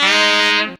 HARM RIFF 9.wav